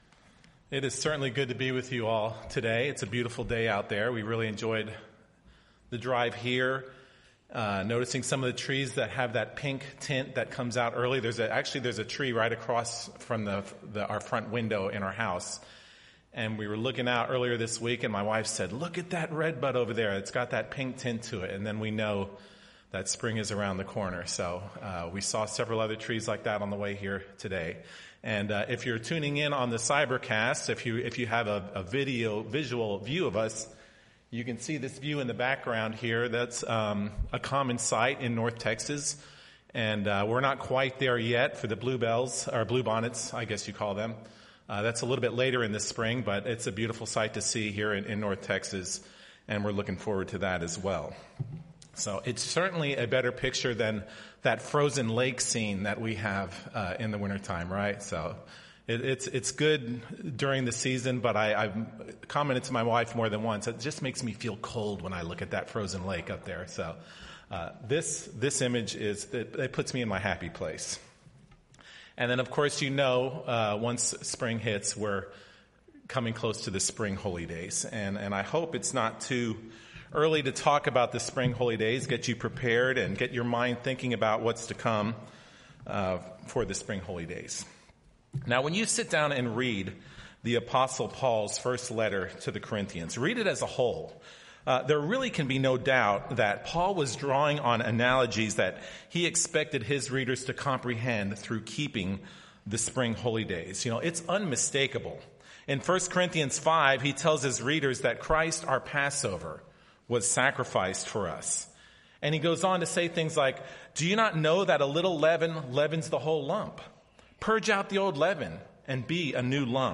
What is the vital difference with the typical church member who struggles to repent and to overcome sin vs a sinner such as we find in the fornicator of 1 Corinthians? This sermon will explore that vital difference.